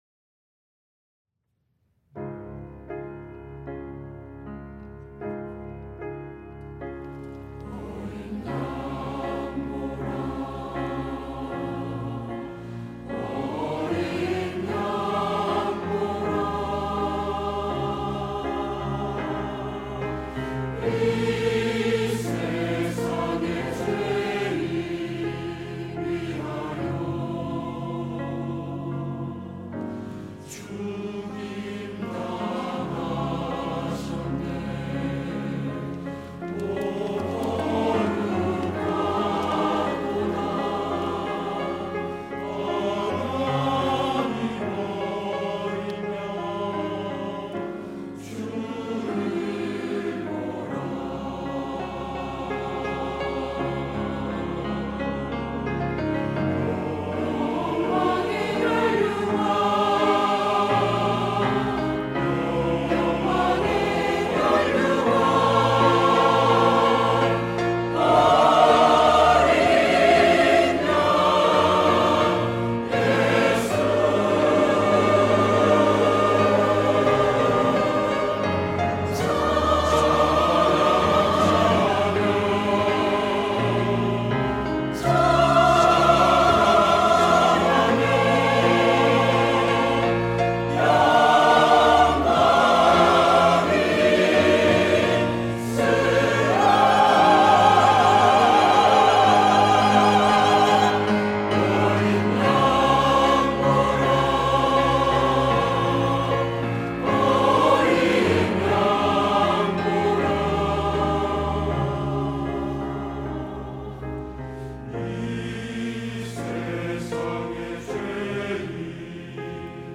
시온(주일1부) - 어린 양을 보라
찬양대